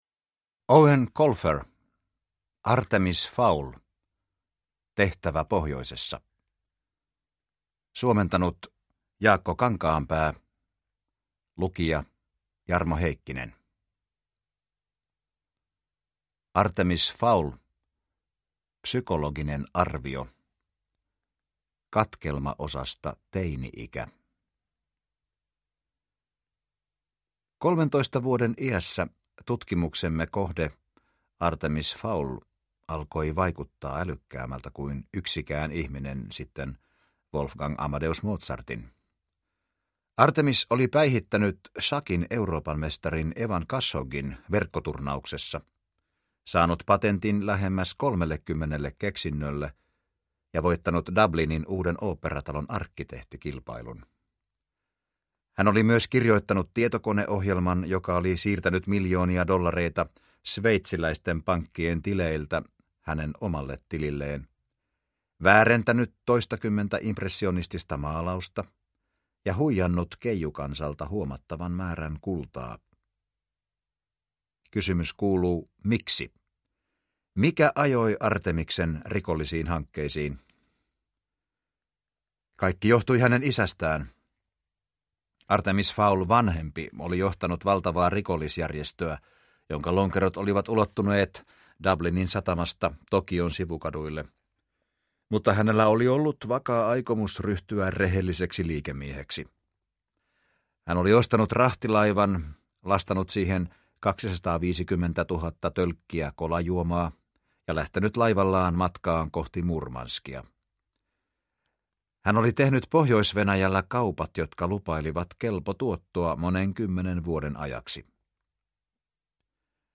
Artemis Fowl: Tehtävä pohjoisessa – Ljudbok – Laddas ner